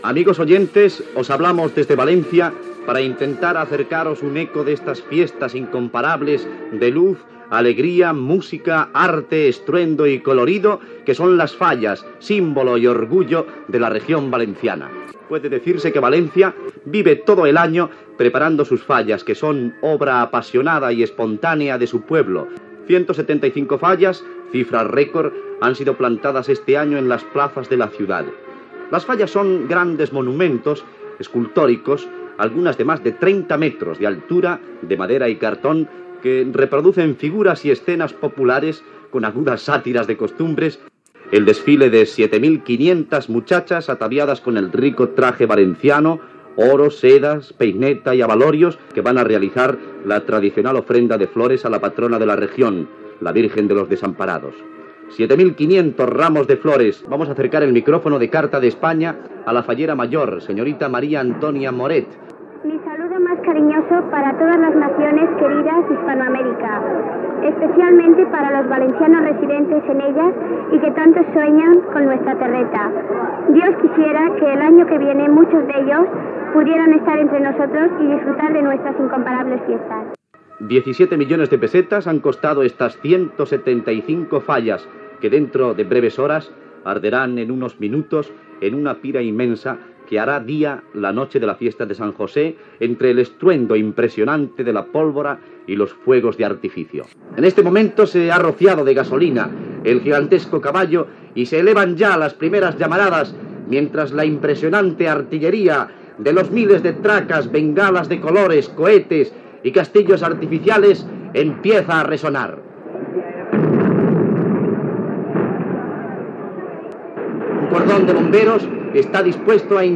Reportatge de les Falles de València